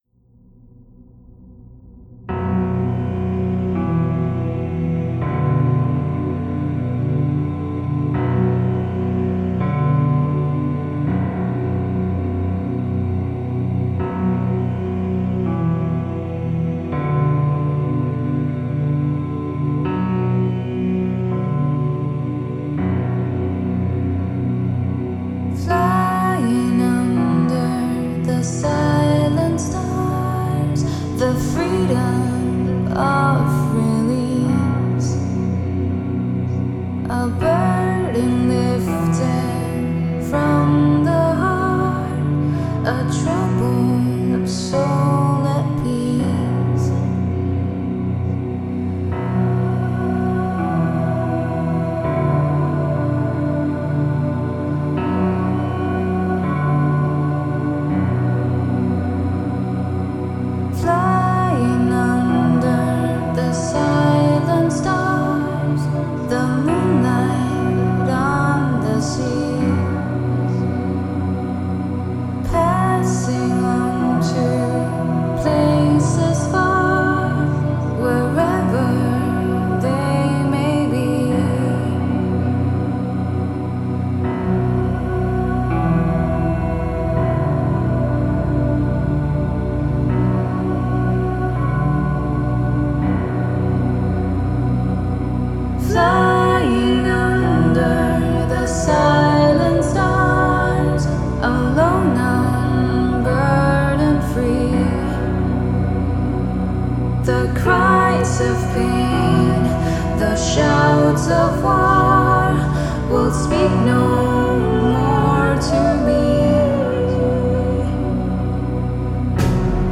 Альтернативная музыка Gothic Metal